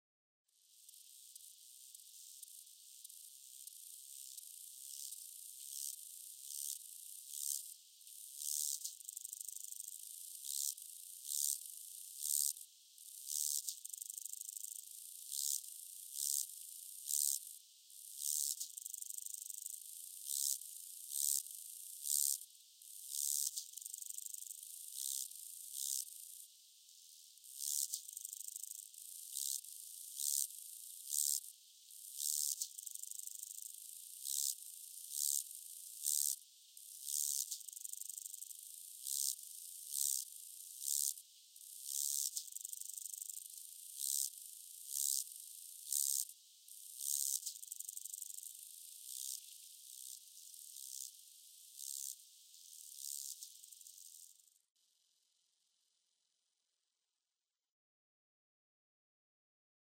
Strandengsgræshoppe - Chorthippus albomarginatus
Friersang.
strandengsgræshoppe2.mp3